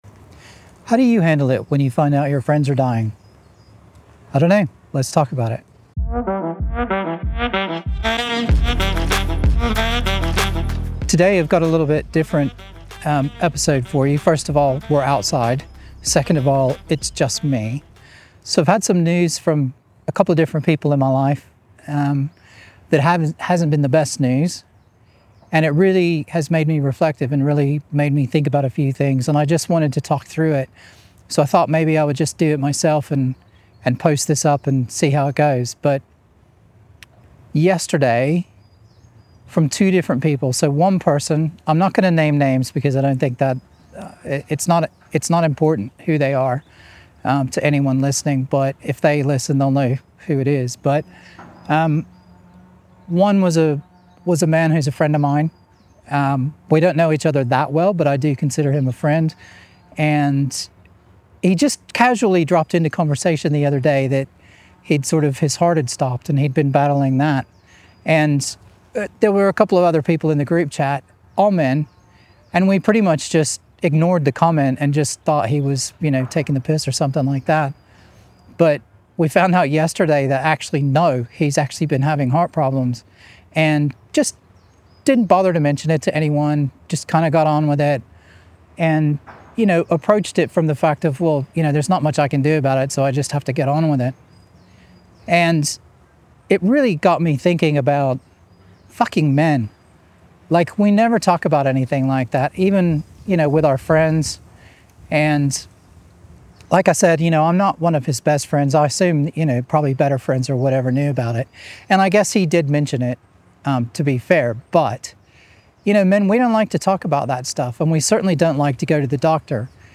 It’s raw.
No guests.
No script.